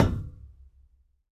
tbd-station-14/Resources/Audio/Effects/Footsteps/hull1.ogg